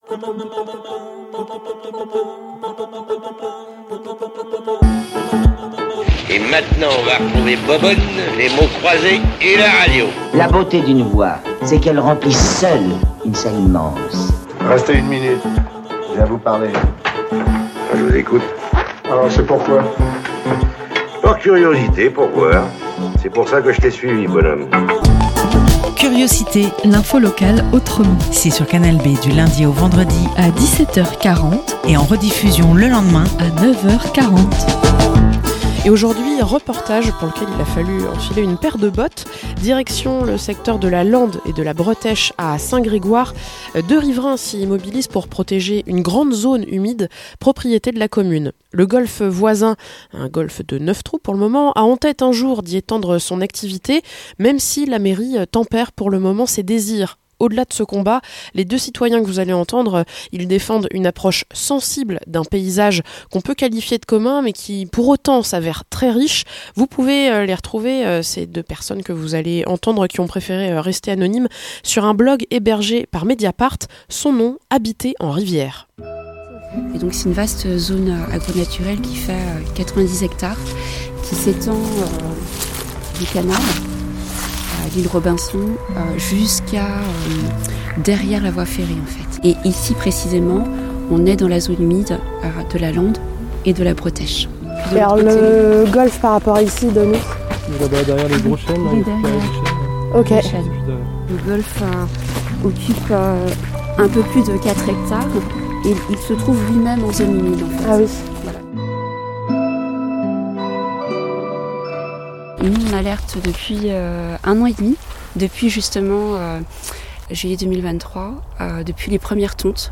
- Reportage à Saint-Grégoire où des riverains défendent les prairies humides de la Lande et de la Bretèche.